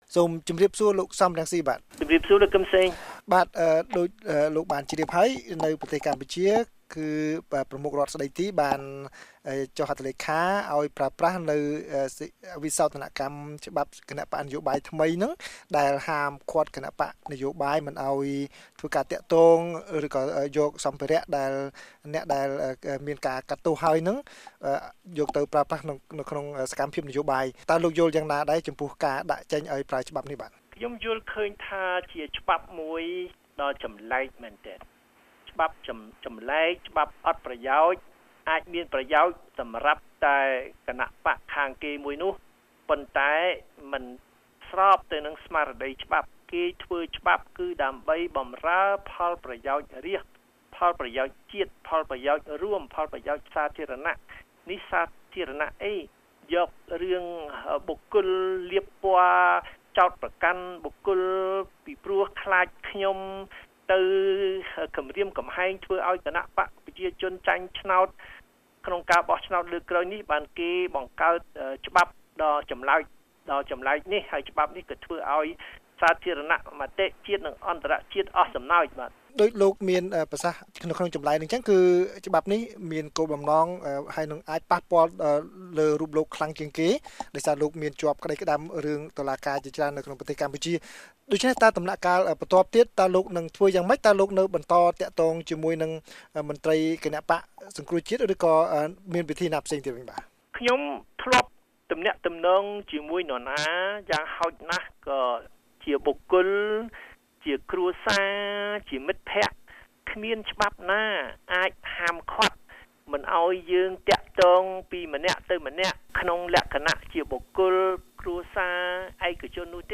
បទសម្ភាសន៍ VOA៖ លោក សម រង្ស៊ី ប្តេជ្ញាបន្តសកម្មភាពនយោបាយទោះបីមានច្បាប់ហាមឃាត់